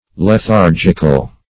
lethargical - definition of lethargical - synonyms, pronunciation, spelling from Free Dictionary
lethargical.mp3